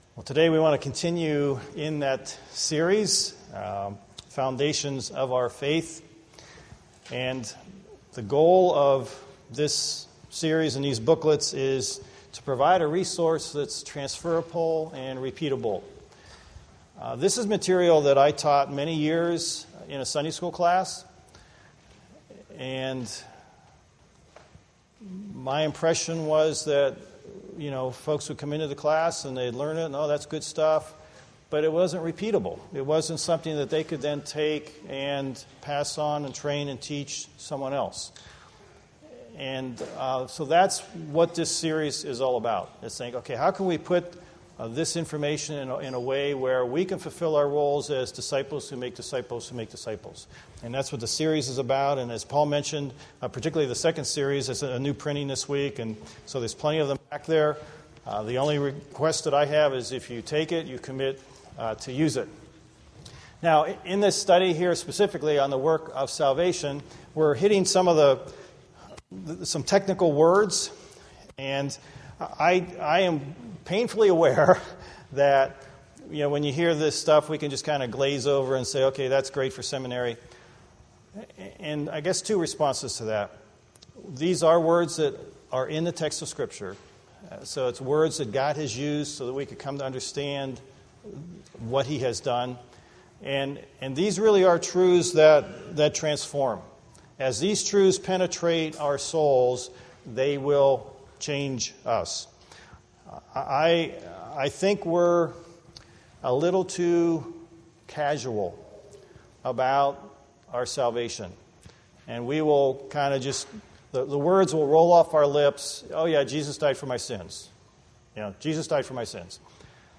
Sermons Archive - Page 7 of 7 - Equipped to Disciple Others